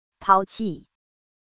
英文單字中文簡易翻譯發音是這樣(台灣女聲)
abandon-chinese.mp3